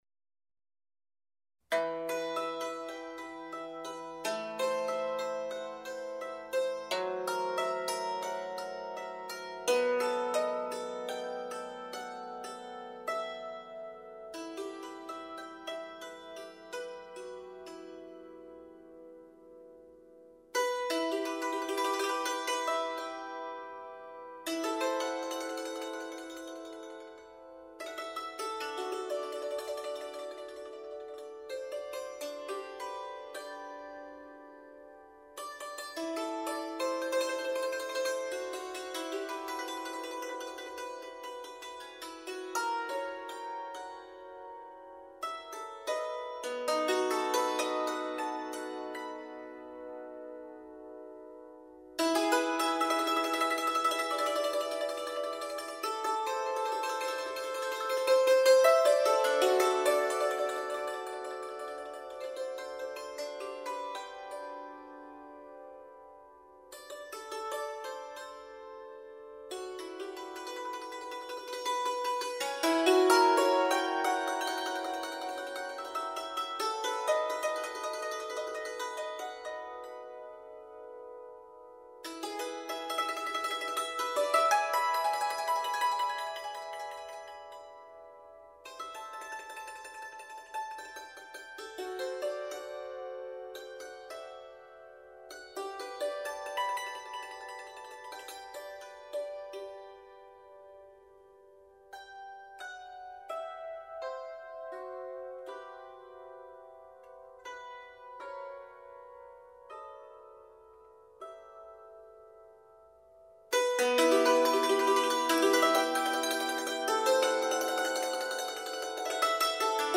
Dulcimer Artist and Story Teller
Russian and Ukrainian folk tunes